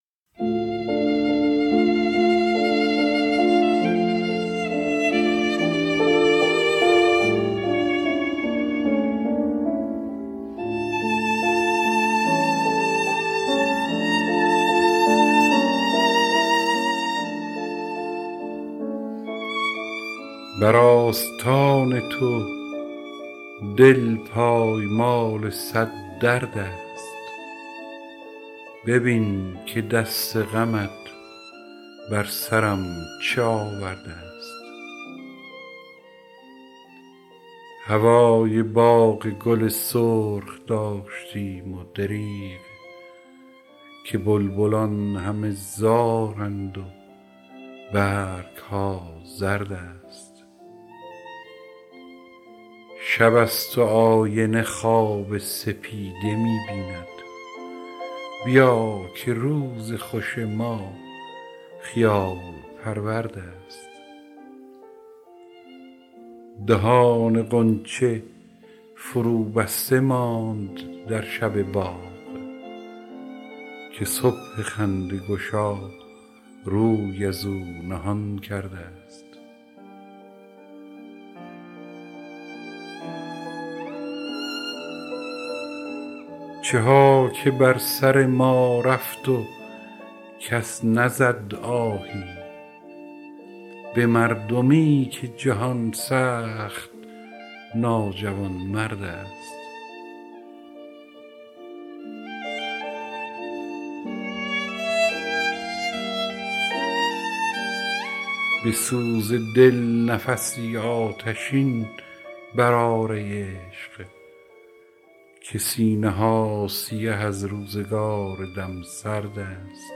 دانلود دکلمه شبگرد با صدای هوشنگ ابتهاج
گوینده :   [هوشنگ ابتهاج]